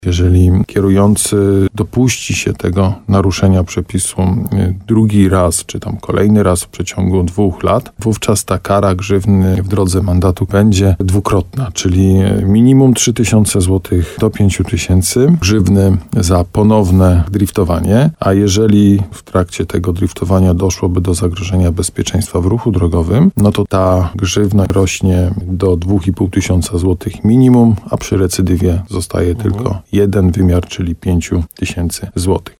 w programie Słowo za Słowo w radiu RDN Nowy Sącz
Rozmowa